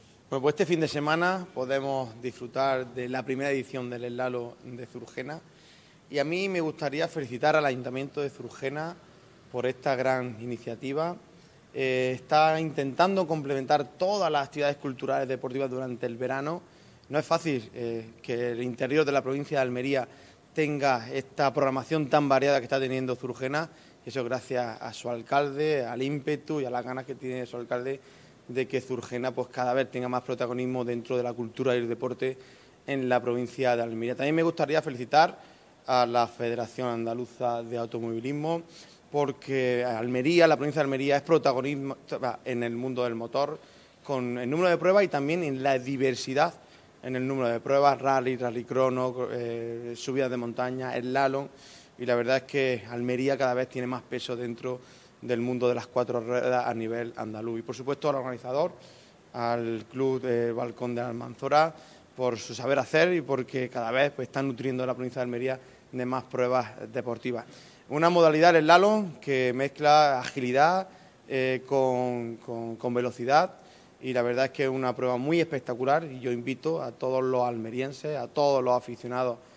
El Pabellón Moisés Ruiz de la Diputación de Almería ha sido el escenario hoy de la presentación oficial del Primer Slalom «Puerta del Almanzora», que convertirá a Zurgena en la capital andaluza del motor el próximo sábado 19 de julio.
Diputado-Slalom-Zurgena.mp3